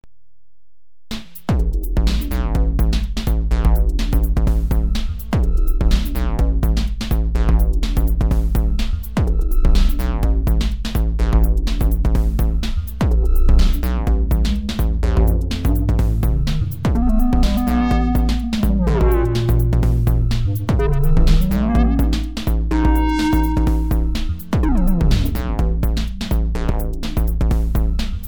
Soft Synth